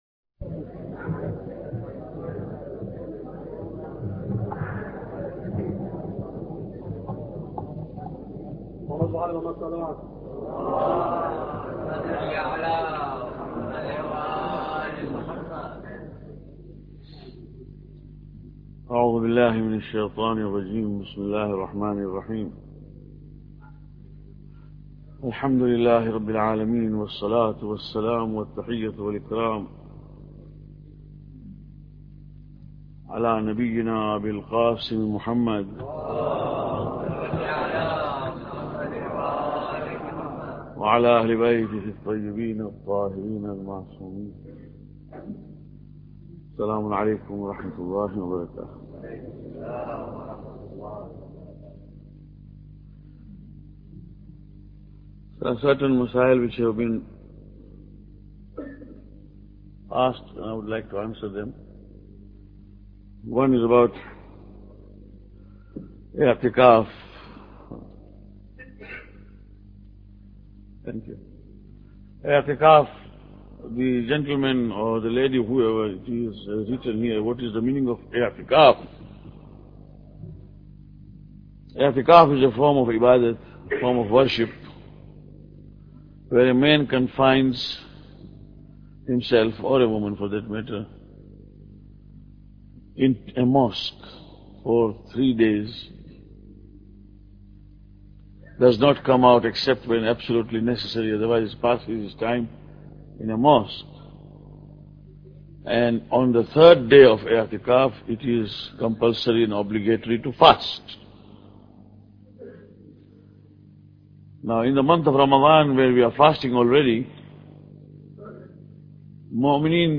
Lecture 15